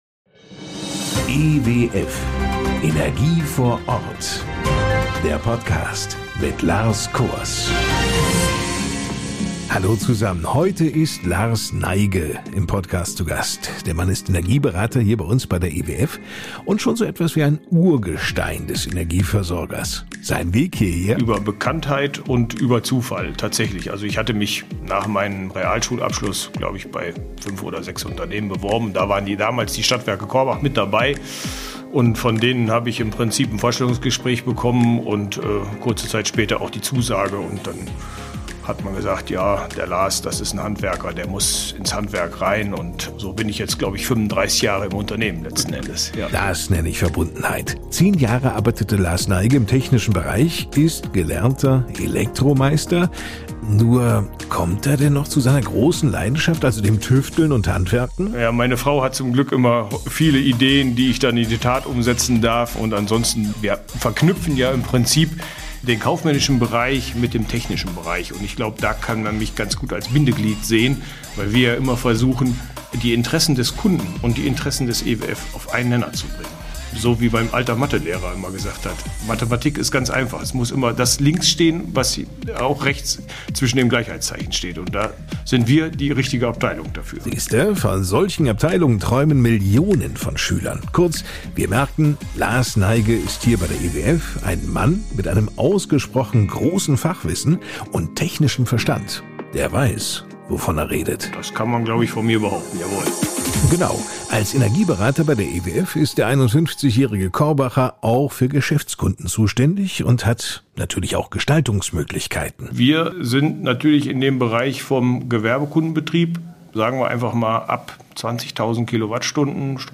Interviews mit Experten aus der Branche, die Ihnen Einblicke in